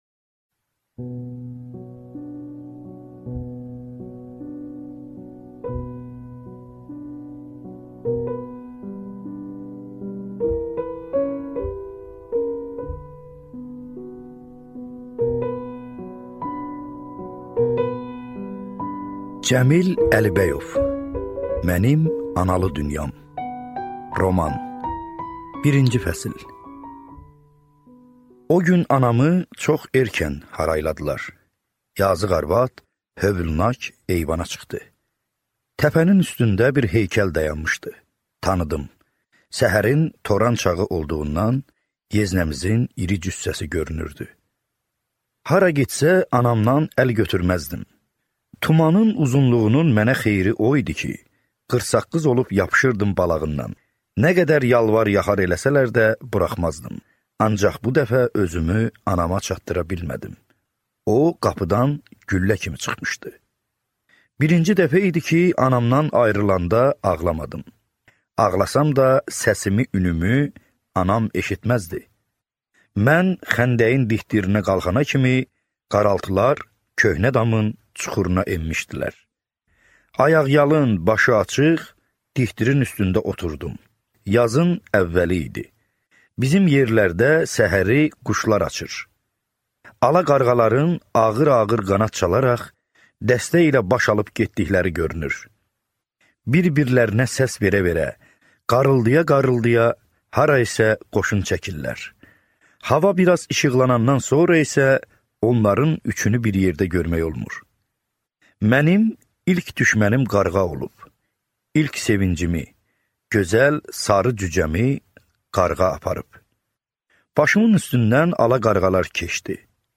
Аудиокнига Mənim analı dünyam | Библиотека аудиокниг